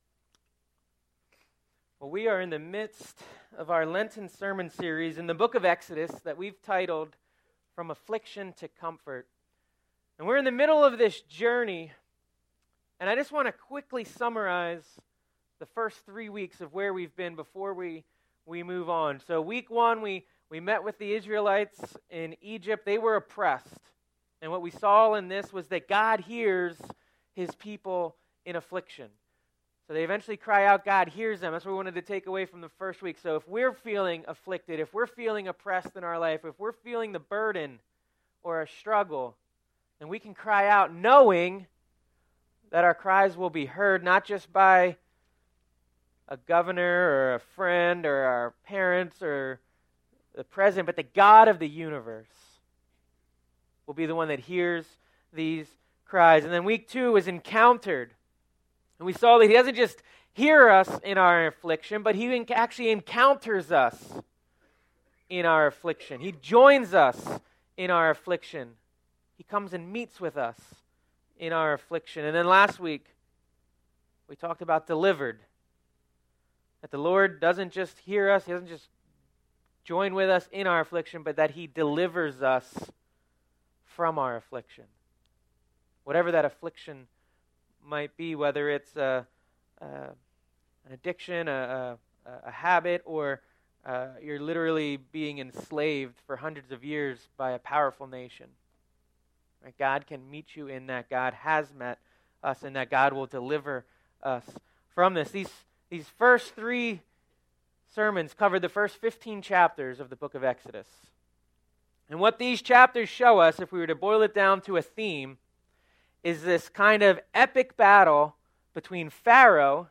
Sermon Series Feb 14 - Oppressed Feb 21 - Encountered Feb 28 - Delivered Mar 06 - Sustained Mar 13 - Directed Mar 20 - Prepared Mar 25 - Interceded (Good Friday drama) Mar 27 - Joined